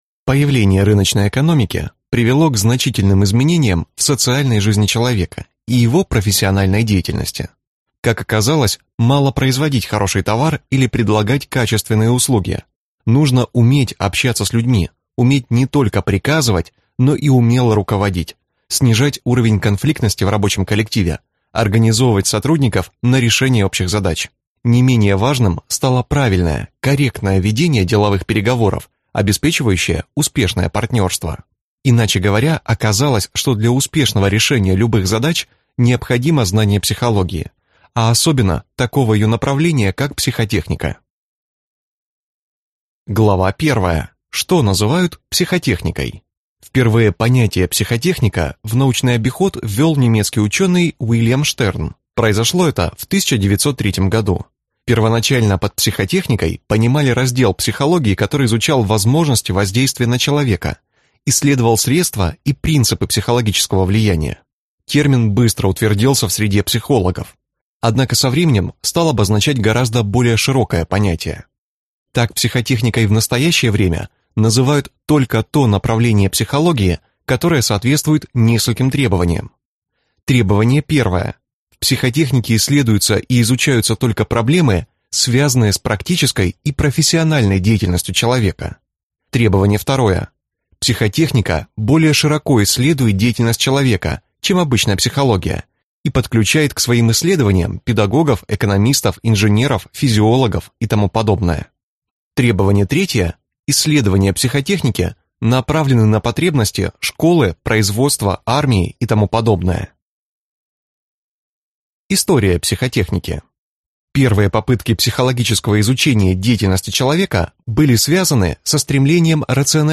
Аудиокнига Психотехники влияния | Библиотека аудиокниг